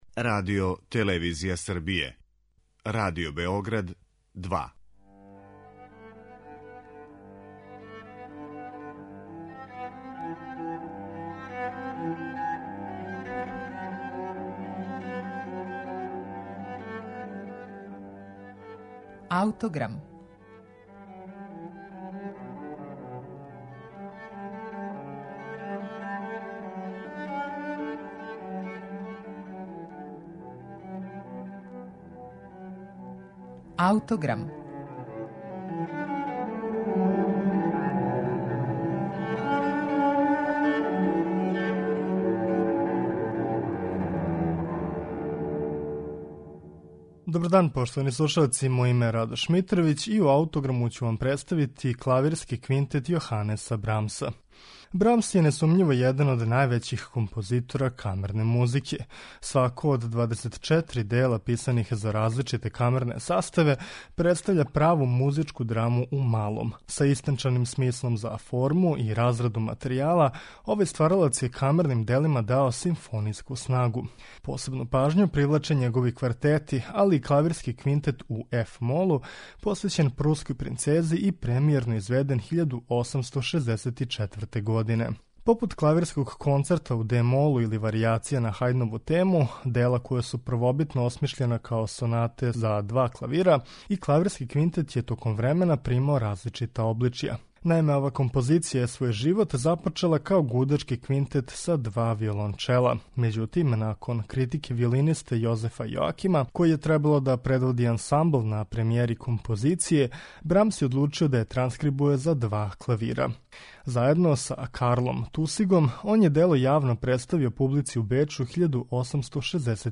Клавирски квинтет Јоханеса Брамса
Брамсов Клавирски квинтет ћете слушати у извођењу Леона Флајшера и квартета Емерсон.